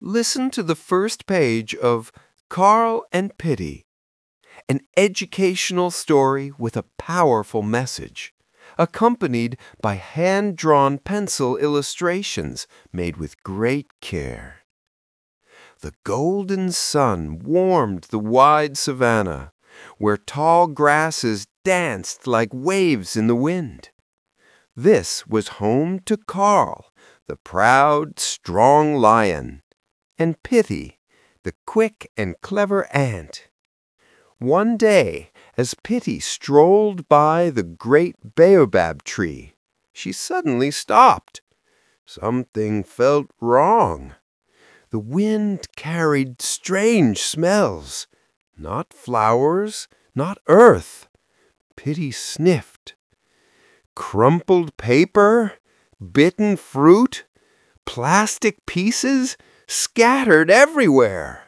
Readings (audio)